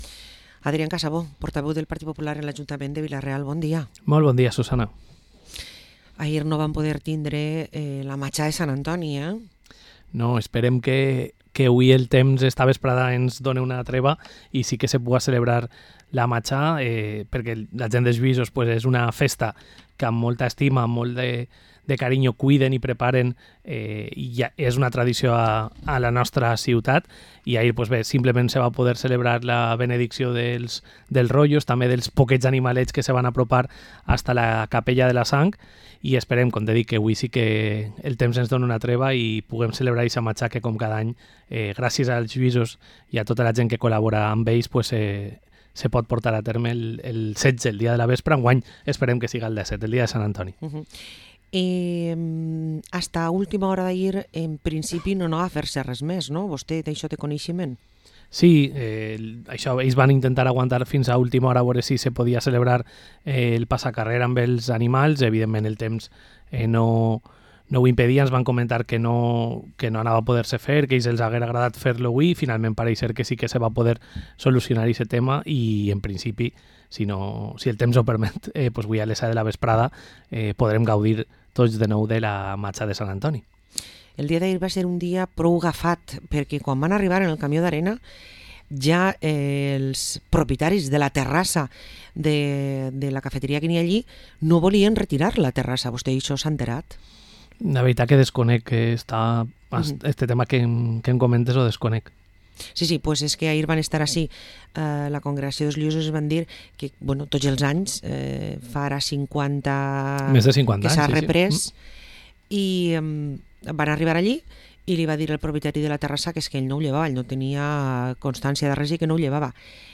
Parlem amb Adrián Casabó, portaveu i regidor del PP a l´Ajuntament de Vila-real